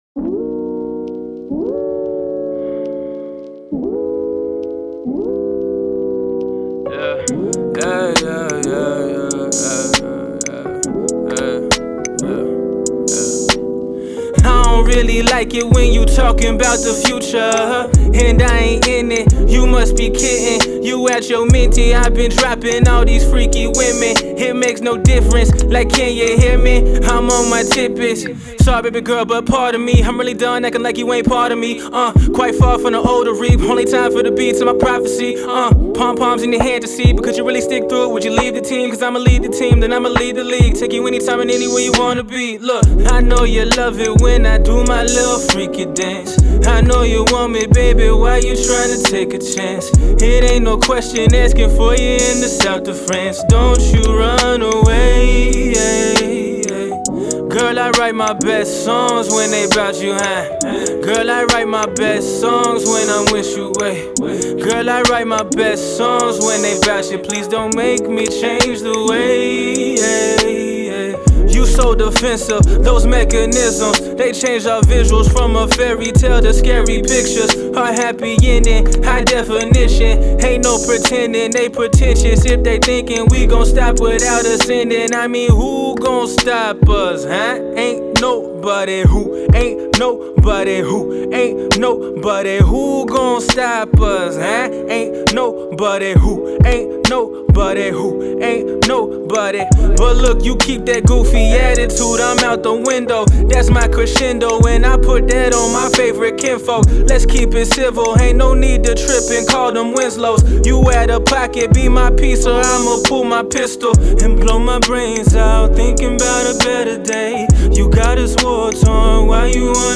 raps and sings about love gone right and love gone wrong.